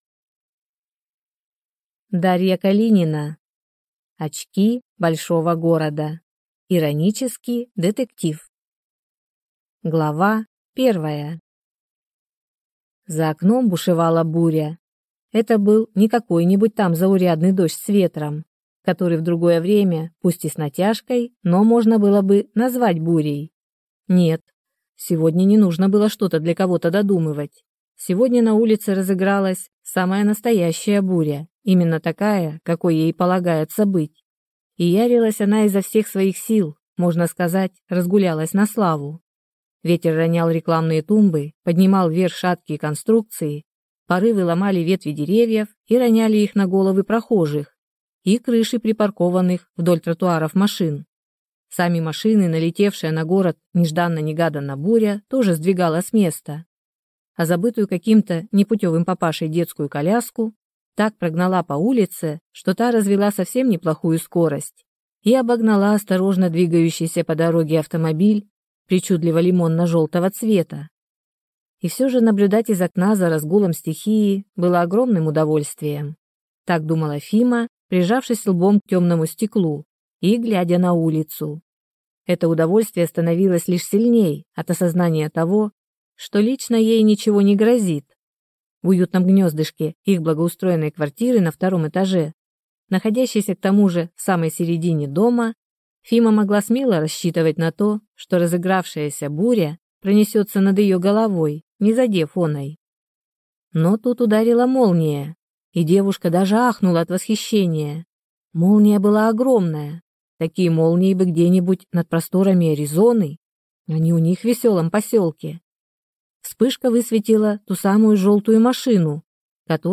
Аудиокнига Очки большого города | Библиотека аудиокниг